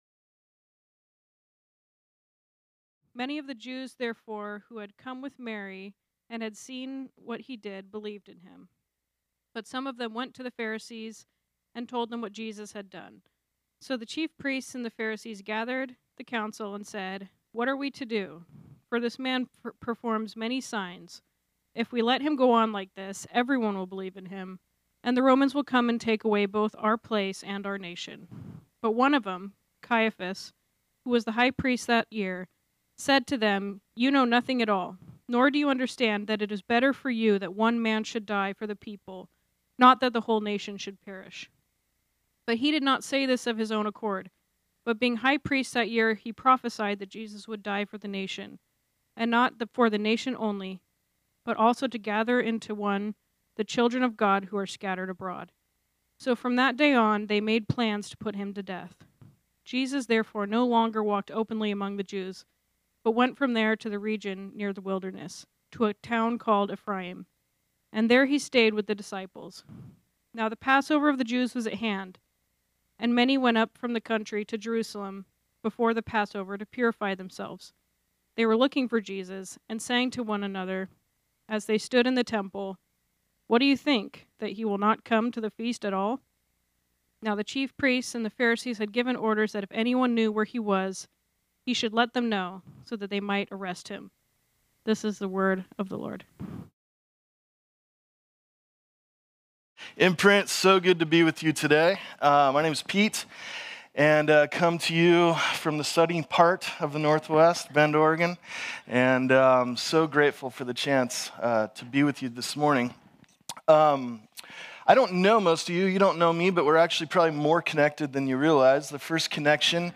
This sermon was originally preached on Sunday, February 23, 2020.